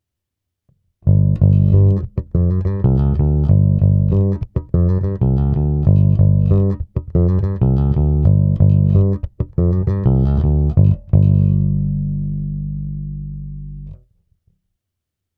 kombinace čistého zvuku a simulace s otočením fáze. Určitě slyšíte výraznou změnu charakteru zvuku oproti předchozí ukázce.